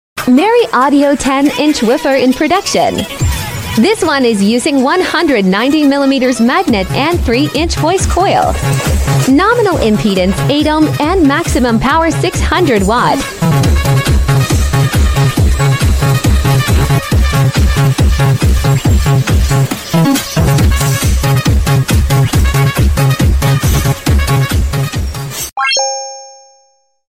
Merry Audio 10” woofer,8 ohm sound effects free download